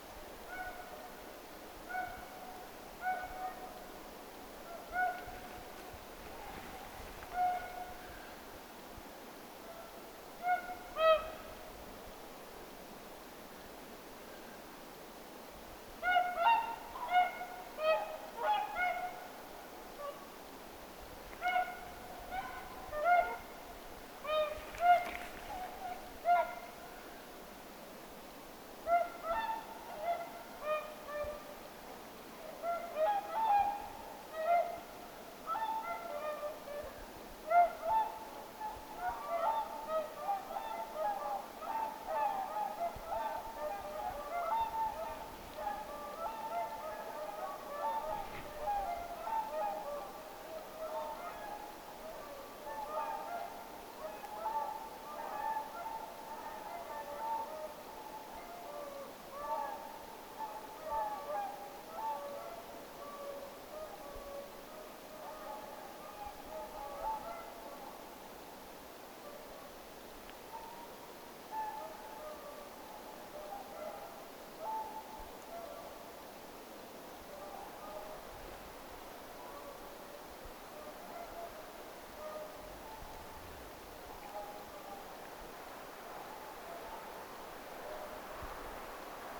lumihanhipaikalta saapuu laulujoutsenia lepopaikalleen,
ääntelyä lennossa niiden saapuessa
lumihanhipaikan_laheisyydesta_ilmeisesti_saapuu_laulujoutsenia_lepopaikalleen.mp3